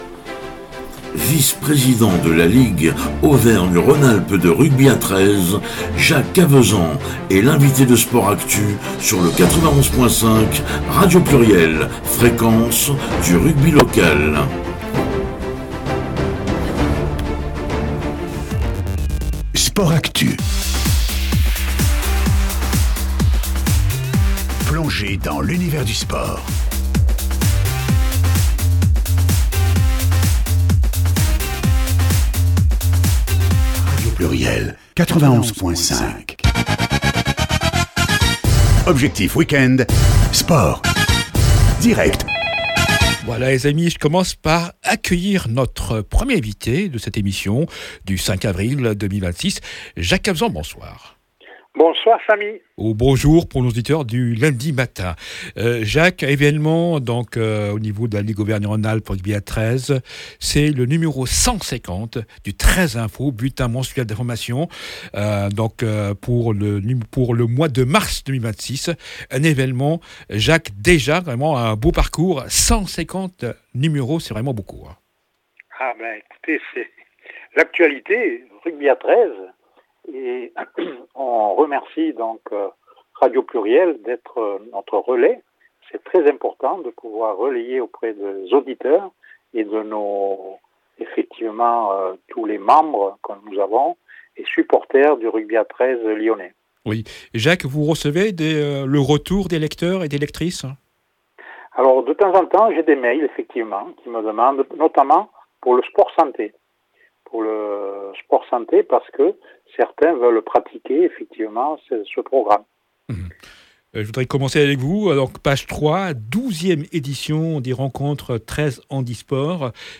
L’interview du président mois de AVRIL 2026